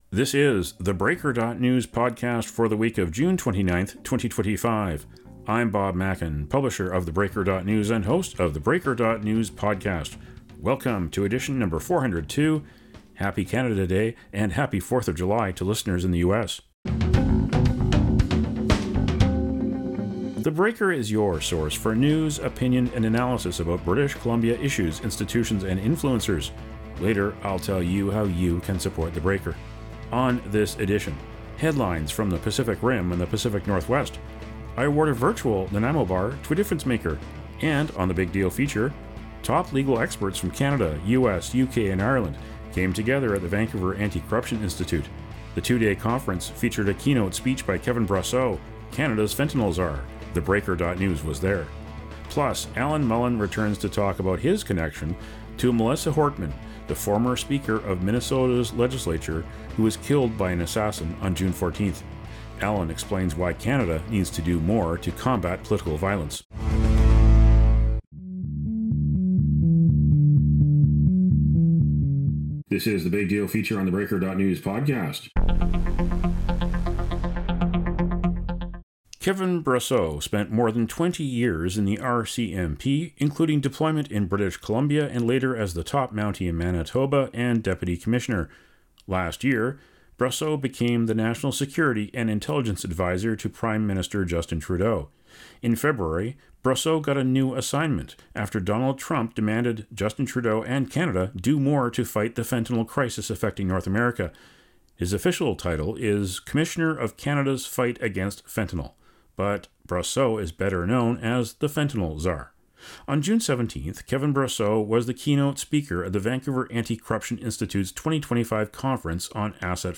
For the week of June 29, 2025: thePodcast before Canada Day and U.S. Independence Day explores two urgent cross-border security issues. Hear Canada’s fentanyl czar, Kevin Brosseau, deliver the keynote speech to the Vancouver Anti-Corruption Institute’s 2025 Asset Recovery conference. The former senior RCMP officer outlines the most-important assignment of his career.